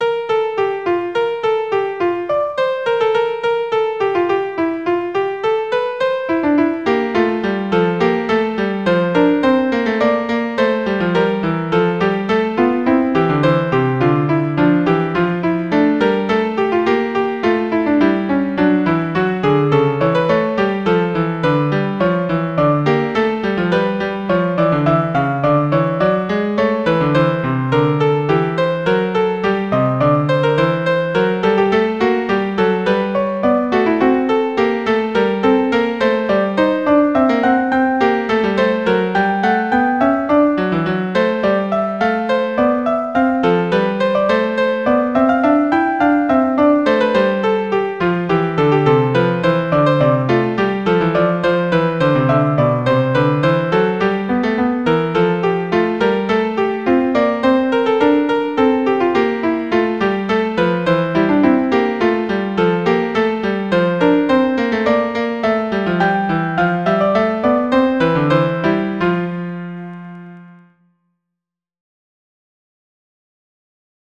2-part invention in counterpoint - Piano Music, Solo Keyboard - Young Composers Music Forum
2-part invention in counterpoint